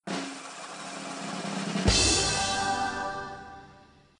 levelwin.mp3